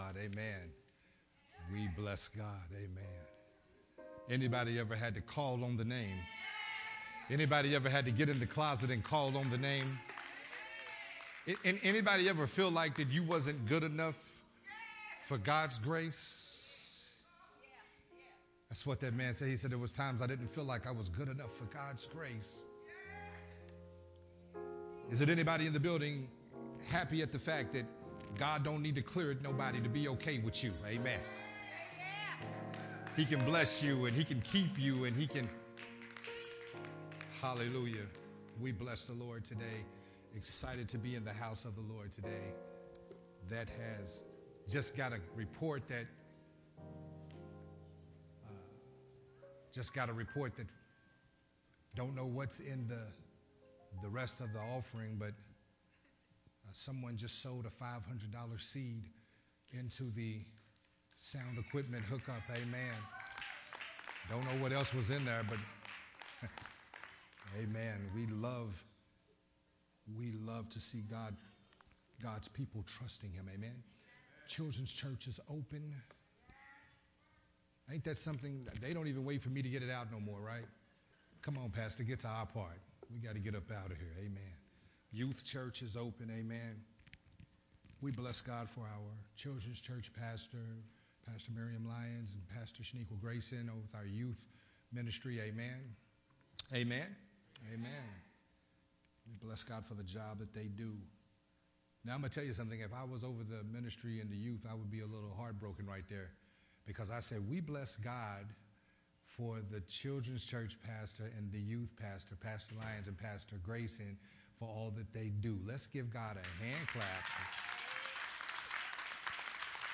Part 2 of the sermon series “Focused”
recorded at Unity Worship Center on January 8th